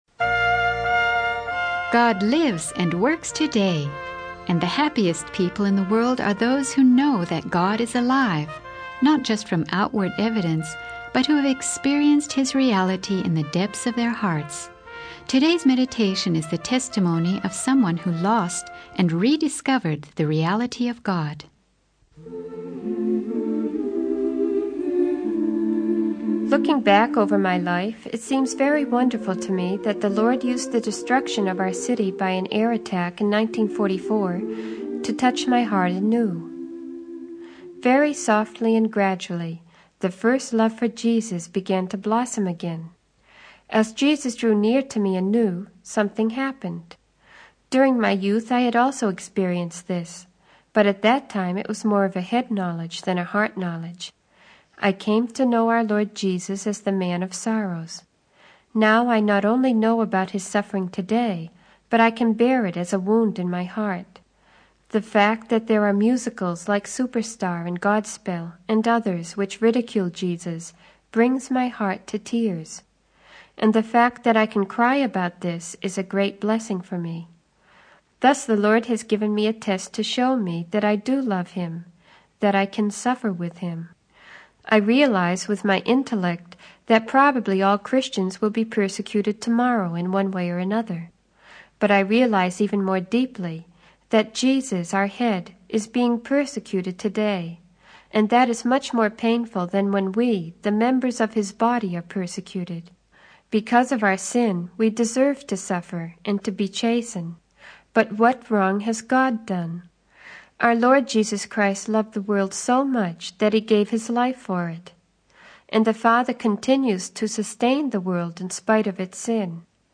In this sermon, the speaker shares their personal testimony of losing and rediscovering their faith in God. They reflect on how the destruction of their city in an air attack in 1944 served as a turning point in their spiritual journey.